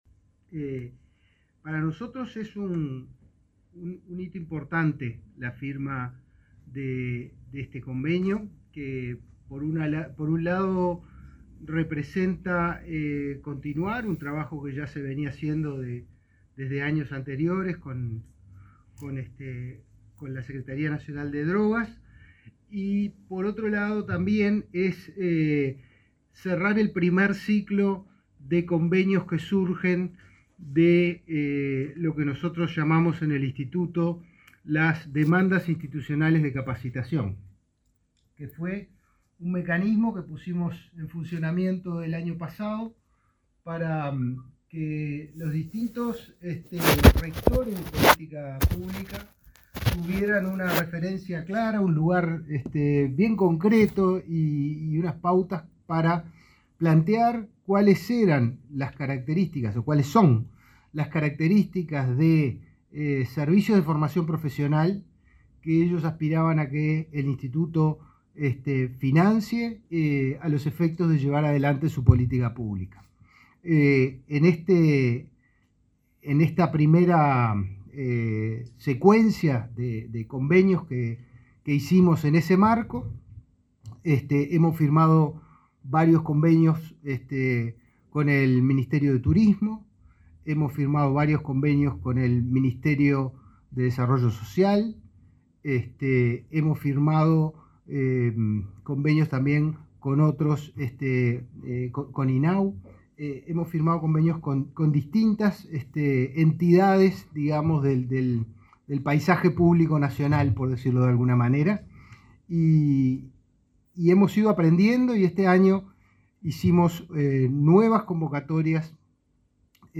Palabra de autoridades en convenio Inefop y JND